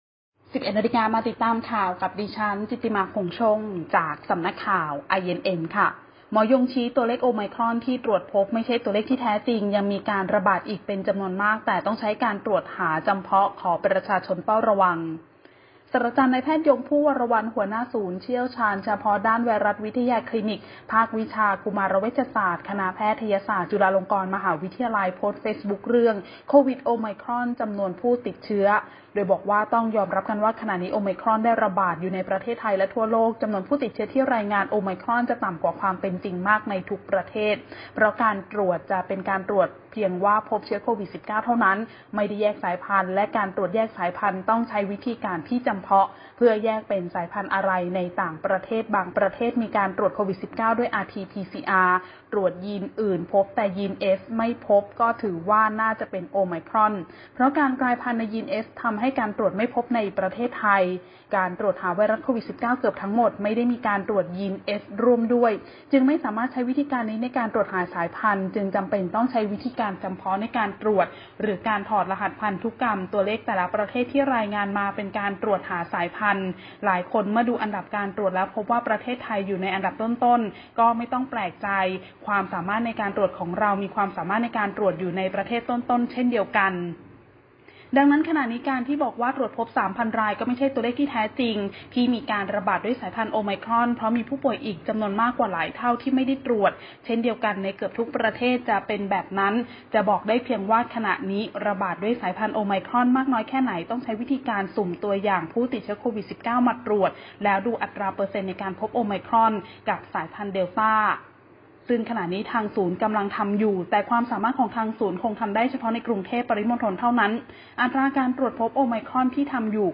ข่าวต้นชั่วโมง 11.00 น.